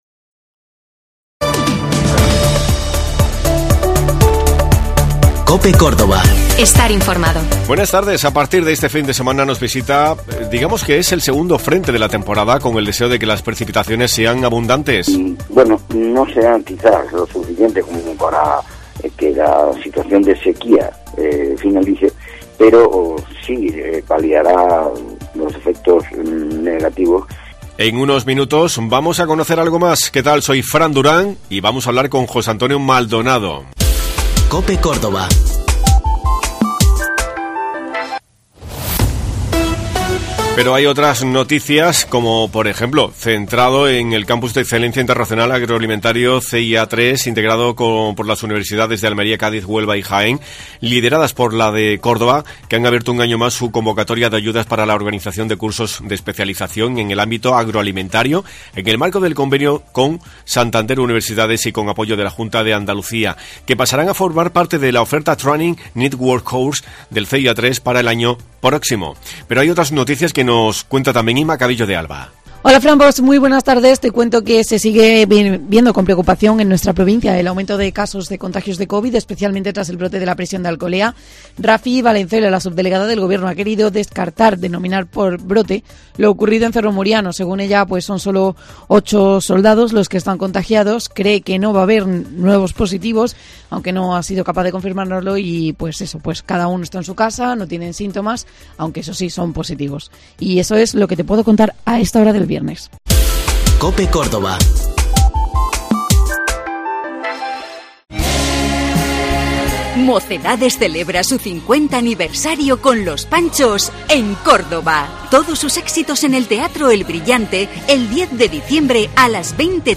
A partir de este fin de semana cruzará una borrasca por la península ibérica que dejará precipitaciones también en Andalucía. Hemos conocido junto al meteorólogo, José Antonio Maldonado, cuál podría ser la evolución durante toda la semana.